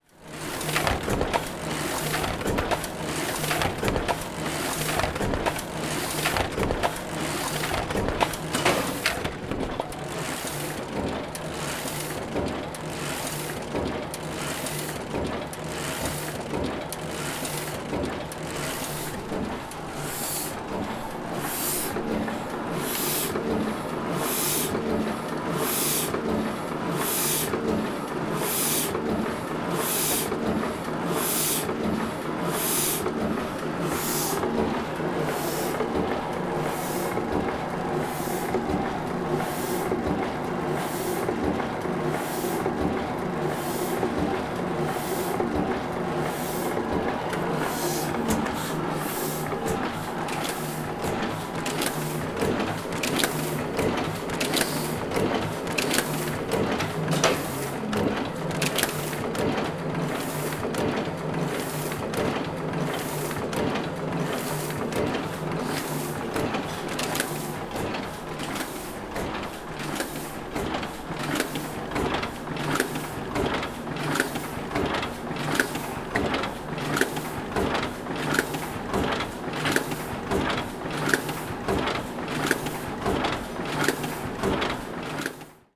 Heidelberg printing press #7
UCS Category: Machines / Industrial (MACHInd)
Type: Alone sound
Channels: Stereo
Conditions: Indoor
Realism: Realistic
Equipment: Zoom H4e